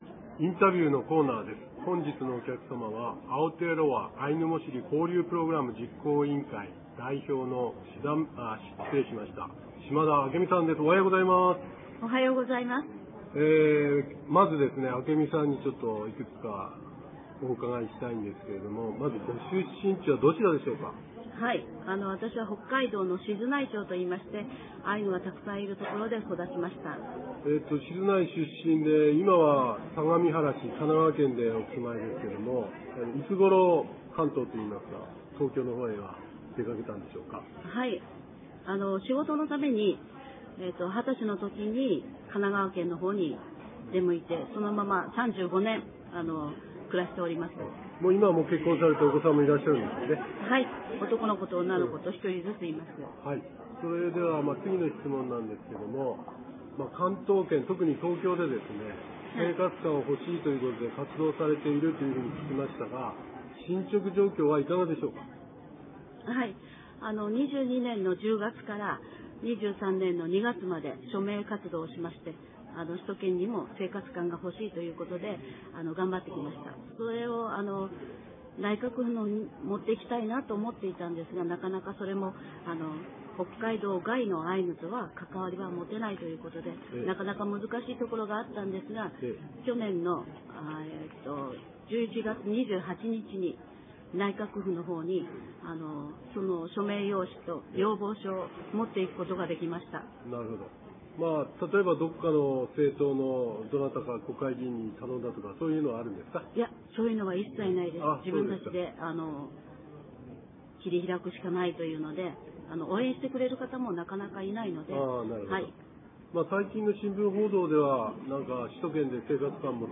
■インタビューコーナー